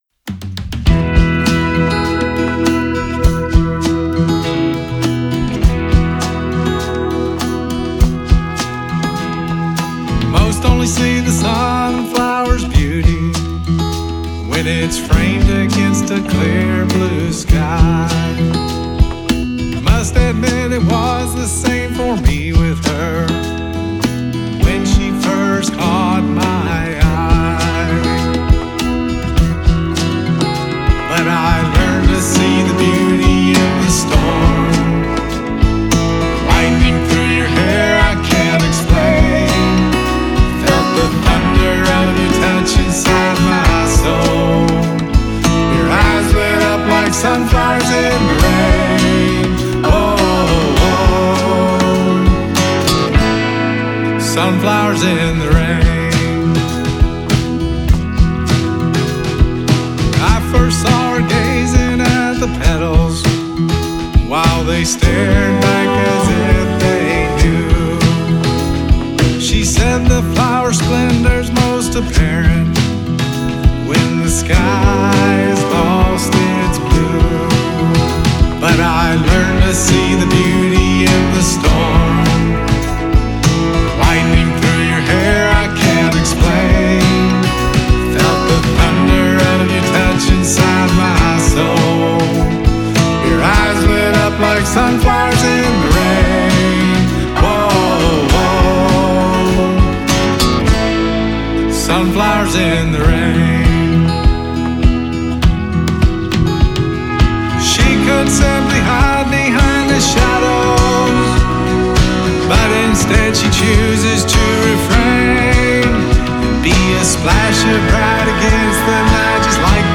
gravados em Nashville.
gravados no renomado Castle Recording Studio em Nashville.
entre o rock de raízes e o folk contemporâneo